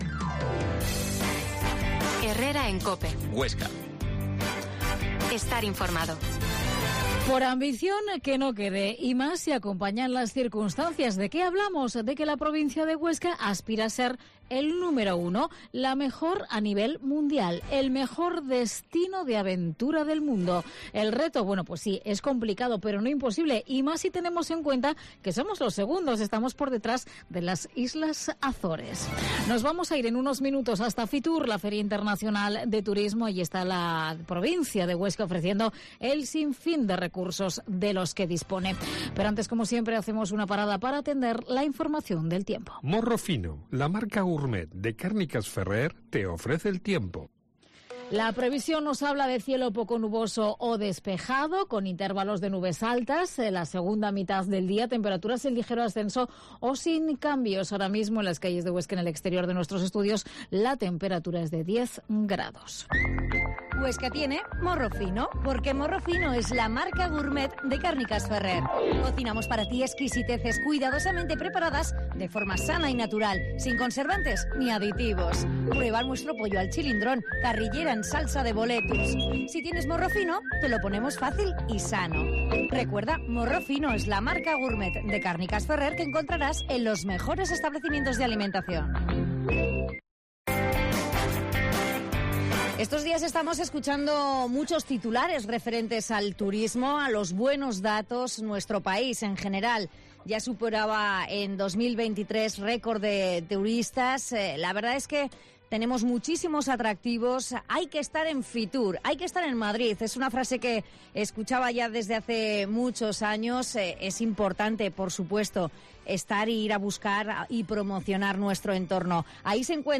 Herrera en COPE Huesca 12.50h Entrevista al diputado de turismo en DPH, Sergio Serra